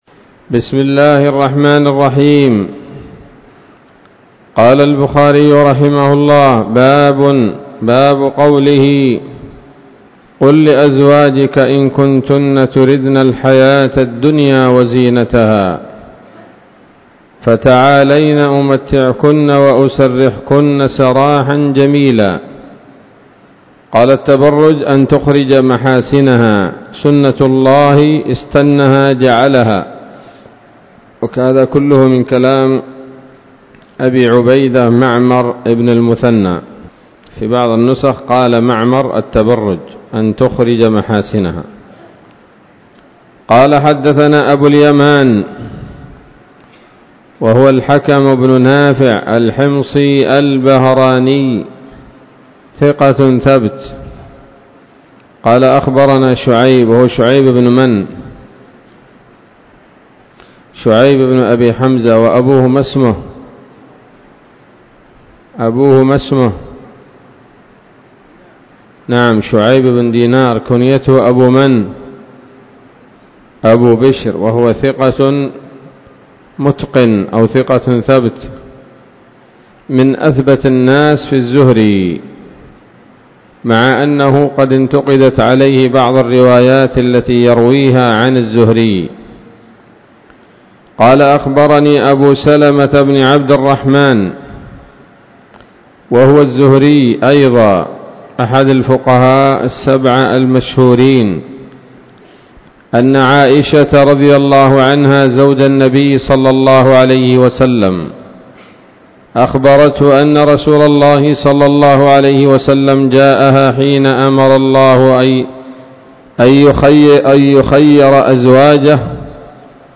الدرس الثالث بعد المائتين من كتاب التفسير من صحيح الإمام البخاري